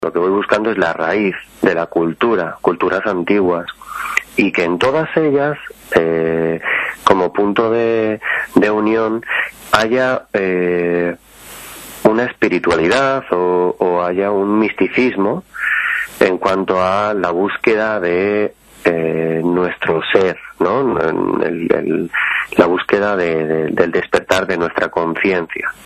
una radiografía sonora de sus gentes y sus costumbres (archivo MP3).